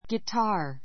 guitar 小 A1 ɡitɑ́ː r ギ タ ー 名詞 ギター play the guitar play the guitar ギターを弾 ひ く ⦣ × a guitar としない.